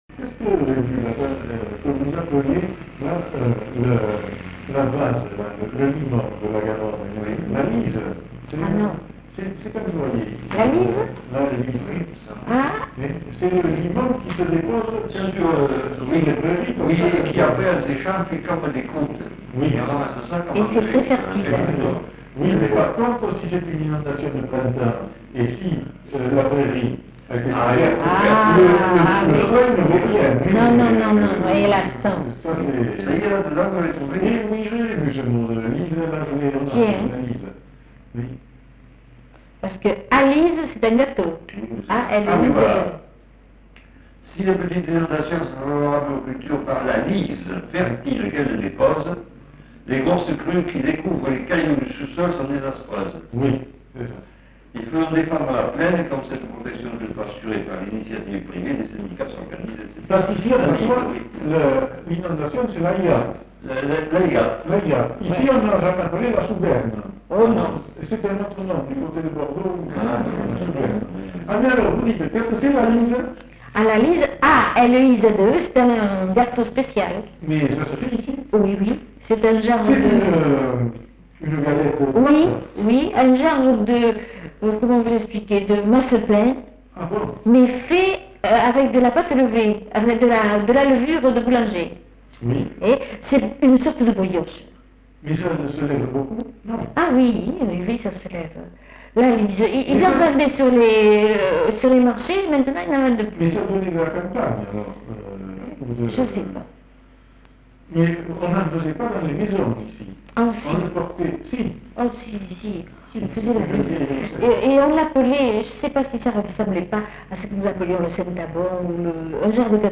Lieu : La Réole
Genre : témoignage thématique